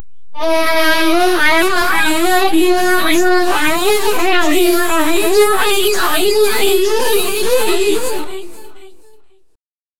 Free AI Sound Effect Generator
poink-rwma3vc3.wav